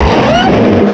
pokeemerald / sound / direct_sound_samples / cries / garchomp.aif